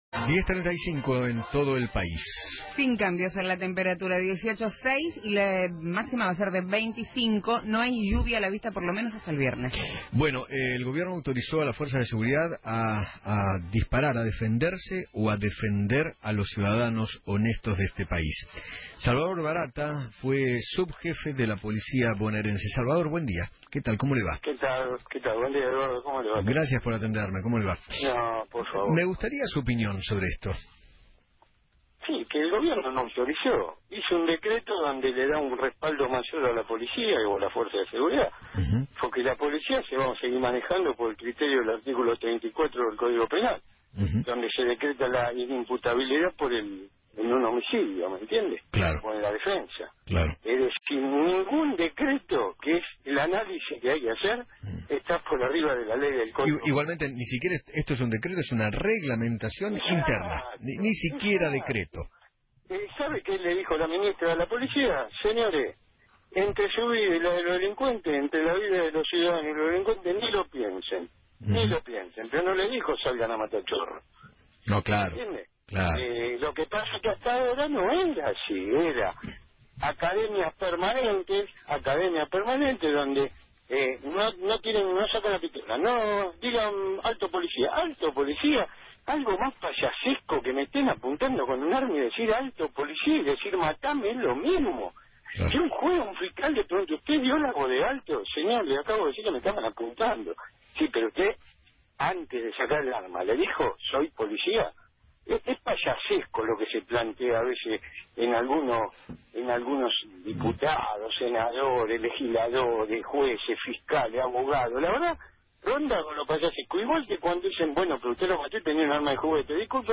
Salvador Baratta, ex Subjefe de la Policía Bonaerense, habló en Feinmann 910 y dijo que  “El Gobierno no autorizó, sino que hizo un decreto que le da un respaldo mayor a la Fuerza de Seguridad, la policía se seguirá manejando por el artículo 34 del Código Penal.”